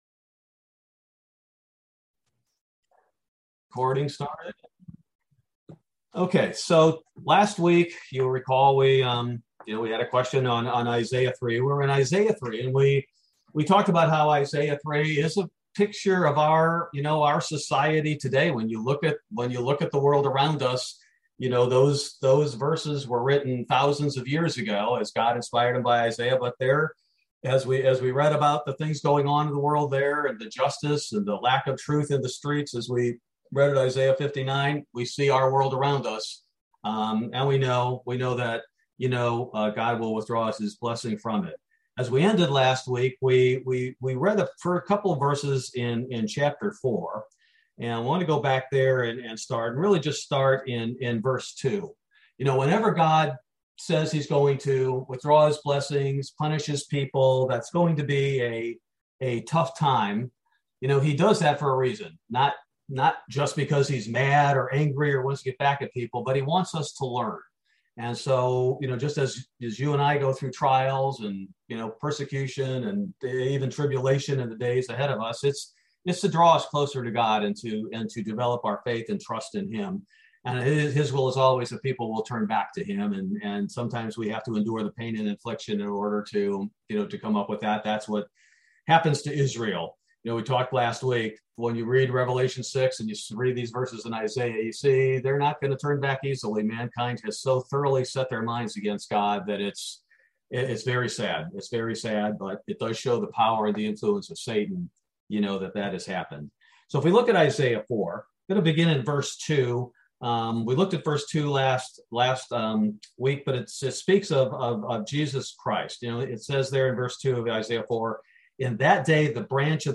Bible Study: July 13, 2022